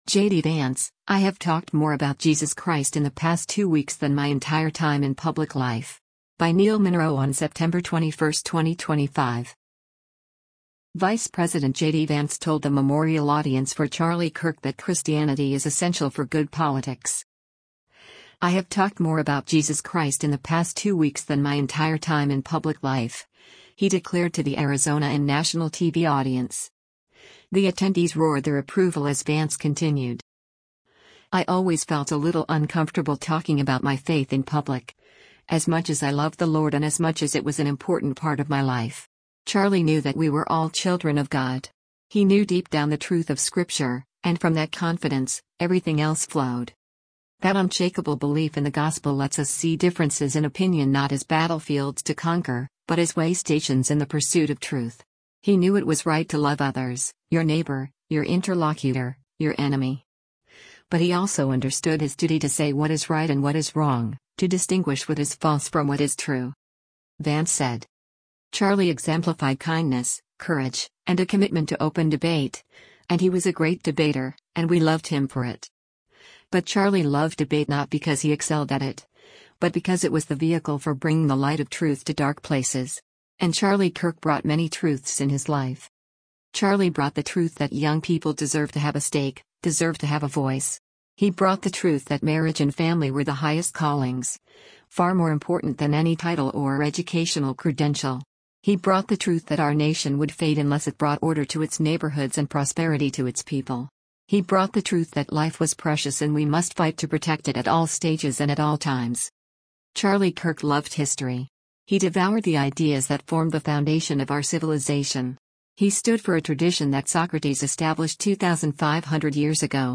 GLENDALE, ARIZONA - SEPTEMBER 21: U.S. Vice President JD Vance speaks during the memorial
Vice President JD Vance told the memorial audience for Charlie Kirk that Christianity is essential for good politics.
The attendees roared their approval as Vance continued: